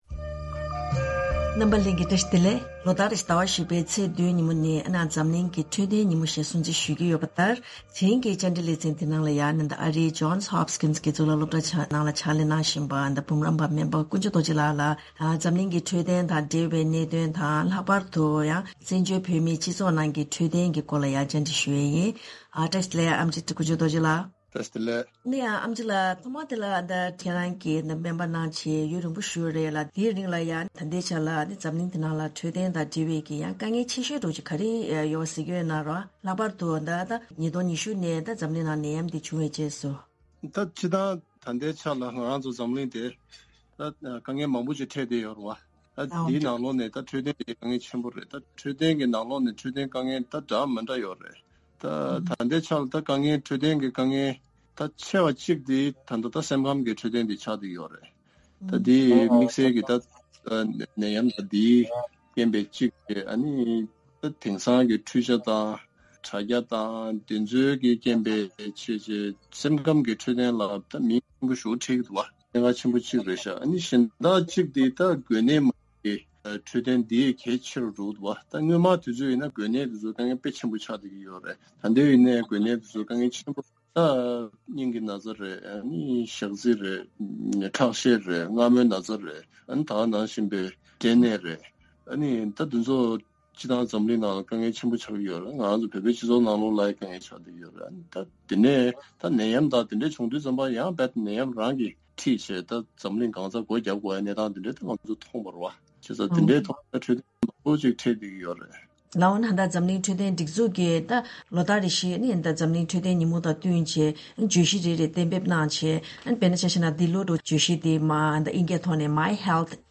བཀའ་འདྲི་ཕྱོགས་བསྡུས་ཞུས་པའི་གནས་ཚུལ།